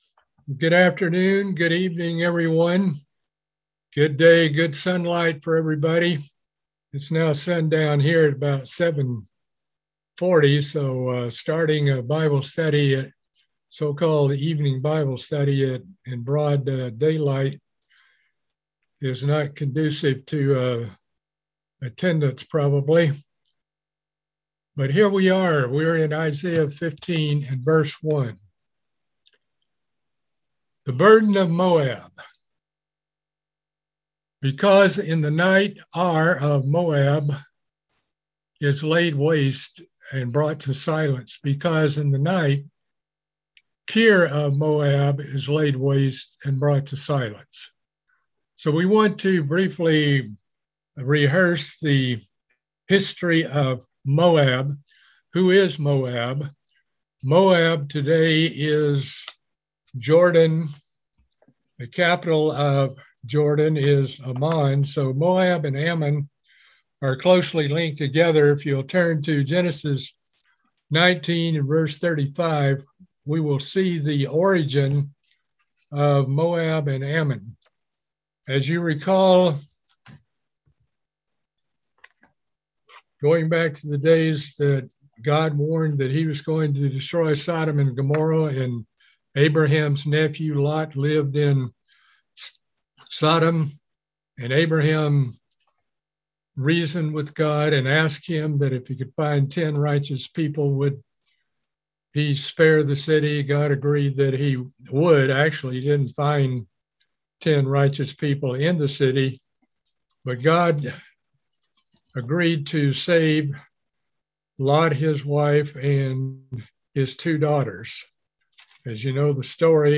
Book of Isaiah Bible Study - Part 13